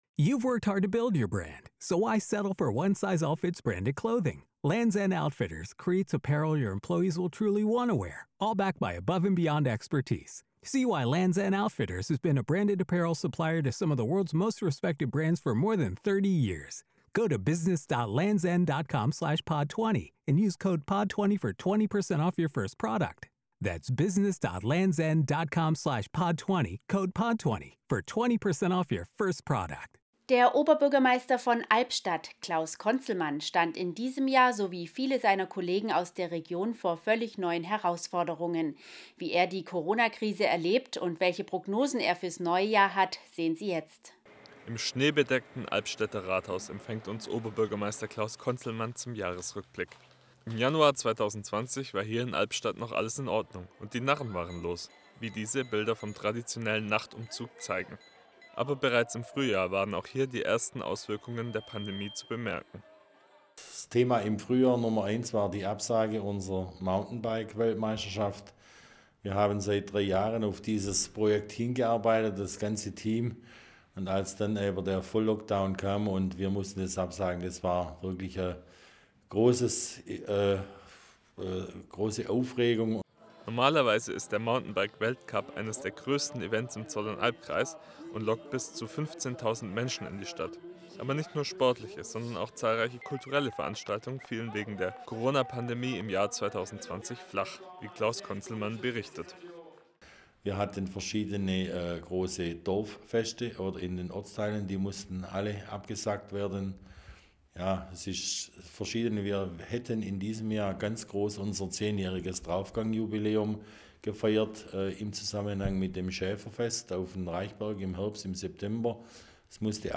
Rückblick 2020 & Ausblick: Albstadt | Interview mit OB Klaus Konzelmann